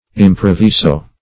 Meaning of improviso. improviso synonyms, pronunciation, spelling and more from Free Dictionary.
Search Result for " improviso" : The Collaborative International Dictionary of English v.0.48: Improviso \Im`pro*vi"so\, a. [L. improvisus unforeseen; cf. It. improvviso.] Not prepared or mediated beforehand; extemporaneous.